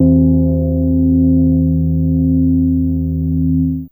ChimesC2C2.wav